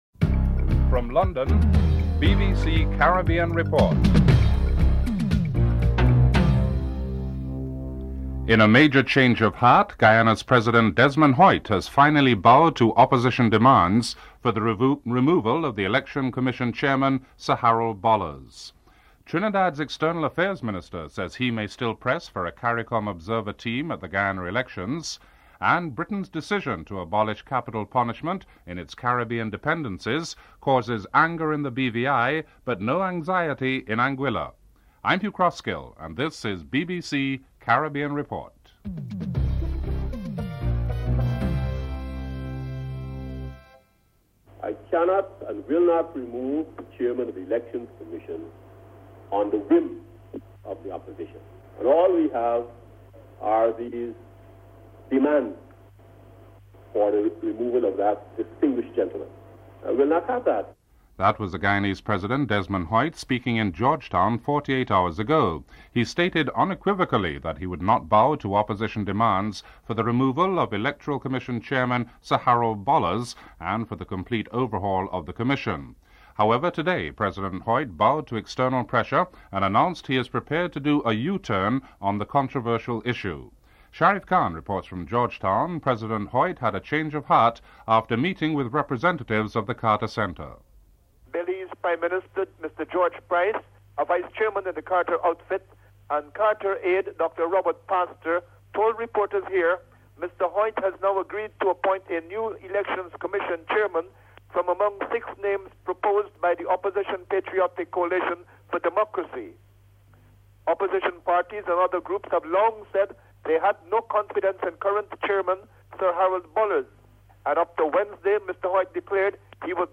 Segment 2 contains a clip of Mr. Desmond Hoyte's earlier statement indicating that he would not bow to the demands of Opposition Parties to remove Sir Harold Bollers as Chairman of the Electoral Commission.